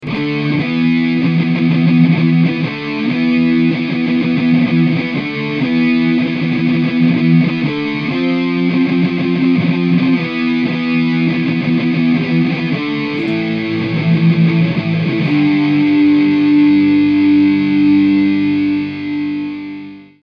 Here is a basic example using power chords:
By merely listening to this example, you can hear a clear sense of direction being created by the power chords. Here, the E5 power chord is the “home” chord of the riff.
In this case, the scale I chose to use is the E Phrygian scale; however, it is not particularly important which scale is being used here.
In the beginning of the riff the D5 is heard as going toward the E5.
Finally the riff ends with A5 going to G5.
powerchordriff.mp3